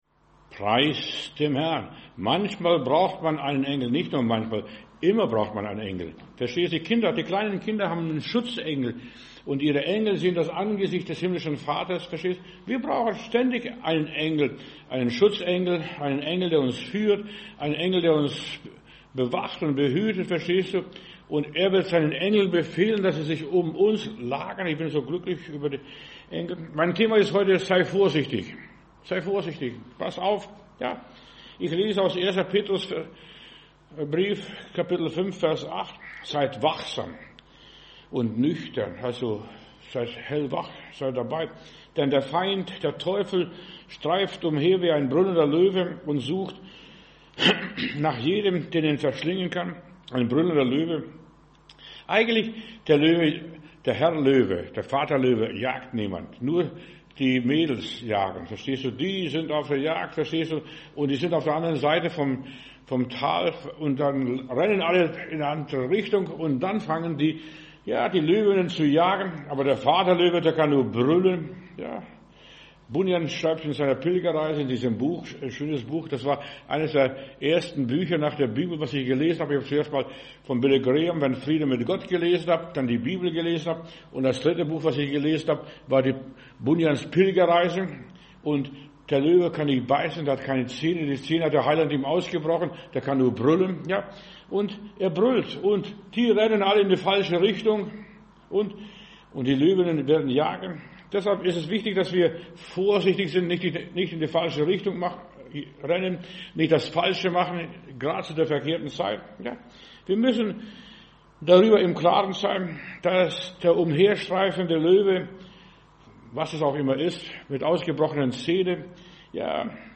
Wer uns unterstützen möchte, kann dies hier tun: ♥ Spenden ♥ Predigt herunterladen: Audio 2024-11-30 Sei vorsichtig Video Sei vorsichtig Facebook Twitter WhatsApp Telegram Email